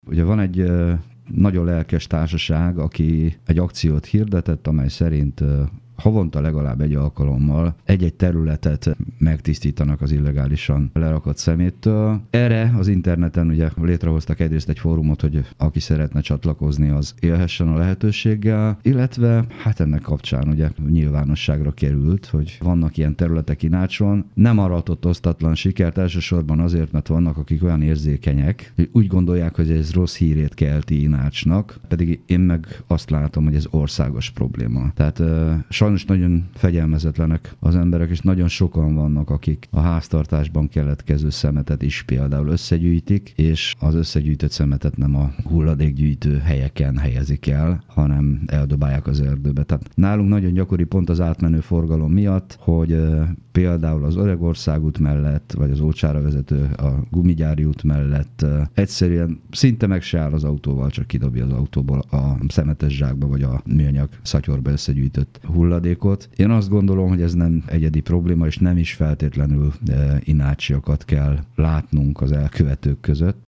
Dr. Gál Imre polgármester arról beszélt rádiónkban, országosan hatalmas probléma a szemetelés.